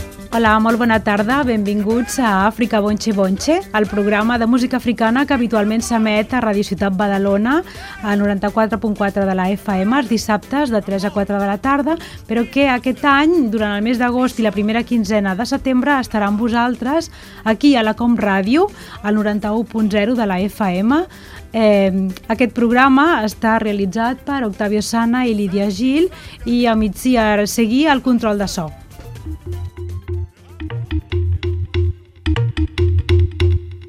Identificació del programa i de l'emissora i noms de l'equip
Musical